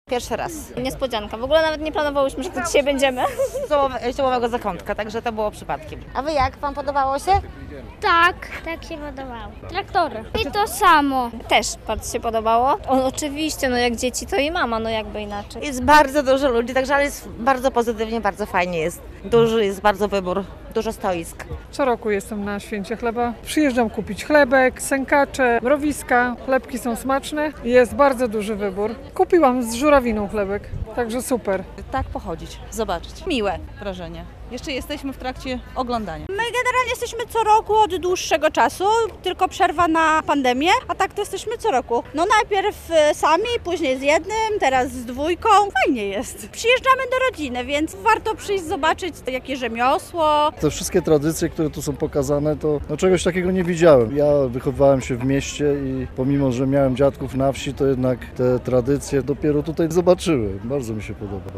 Pokazy, konkursy, koncerty i tysiące odwiedzających - Muzeum Rolnictwa gościło w niedzielę (13.08) Podlaskie Święto Chleba.
Opinie uczestników wydarzenia - relacja